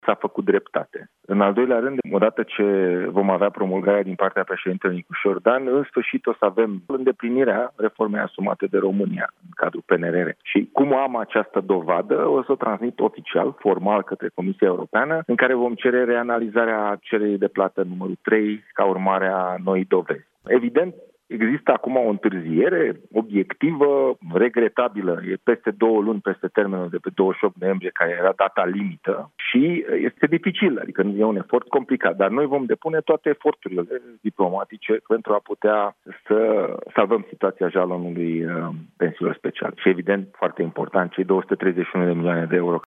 Ministrul Fondurilor Europene, Dragoș Pîslaru: „Dar noi vom depune toate eforturile diplomatice pentru a putea salva situația jalonului pensiilor speciale”